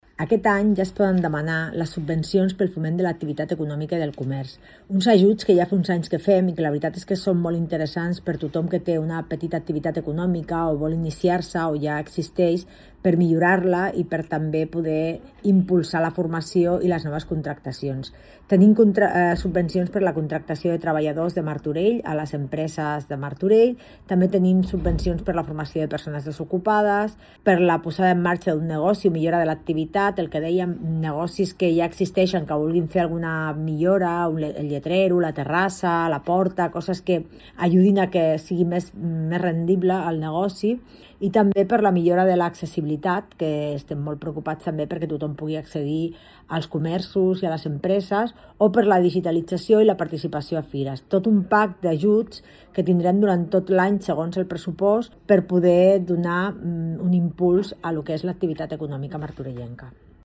Rosa Cadenas, regidora de Promoció Econòmica